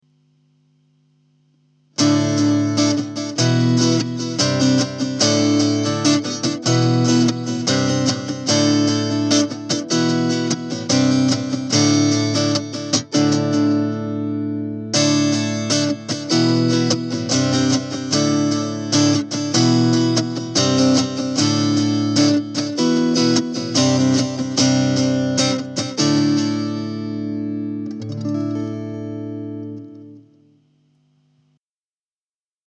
Mooer RepeaterЦифровой дилей.
Acoustikar + Repeater 770,04 Кб
acoustikar_+_reapeter.mp3